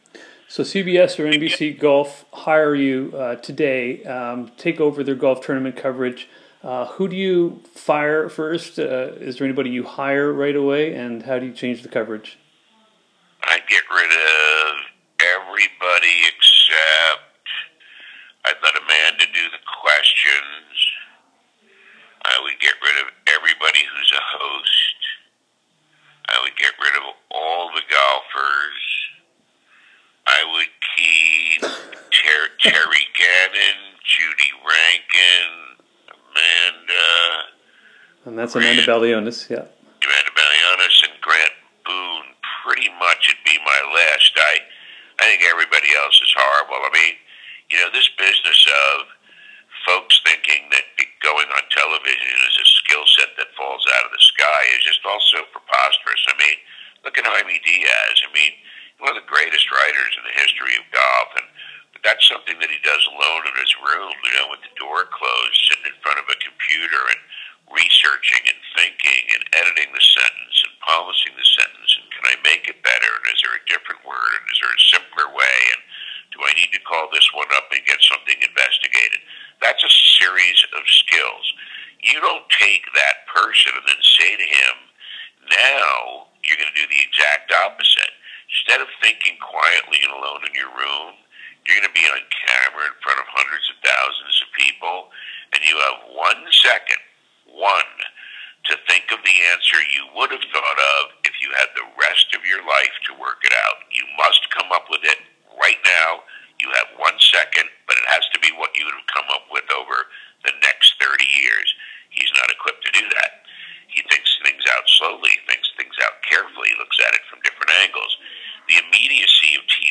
Our telephone connection, regrettably, does not convey the legendary dulcet tones of the man long known as “the voice of golf” with the Golf Channel, from its founding in 1995 through 2001, and as host of his own radio show on PGA Tour Network from 2005 to 2014. But our 50-minute chat, which I’ve broken up into five segments, did showcase Peter’s honesty concerning what he feels is right and wrong about the sport and its media coverage.
There were moments when I felt like I should inject a comment or follow-up question during one of his sometimes stream-of-consciousness replies, but it sort of felt like I’d just be sticking my leg onto the tracks in front of a runaway train.